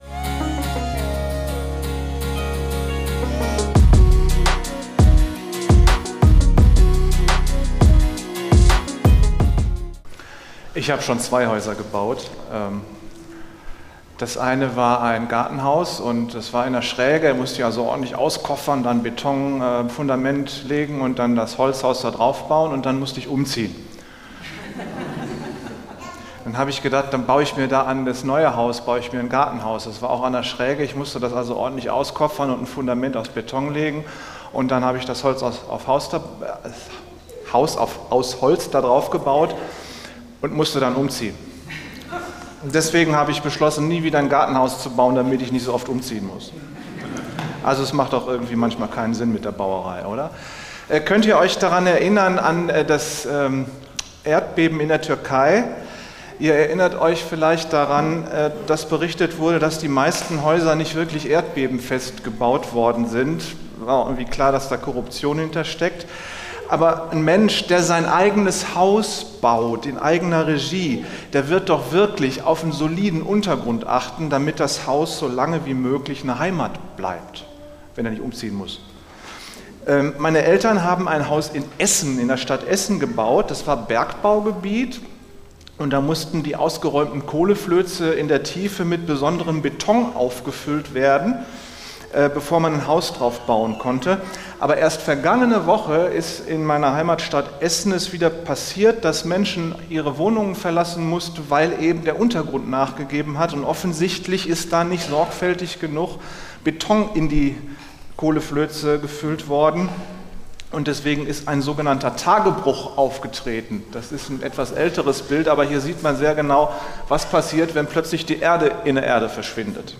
Am 30.6.2024 feierte die Freie evangelische Gemeinde Barmen ein Sommerfest.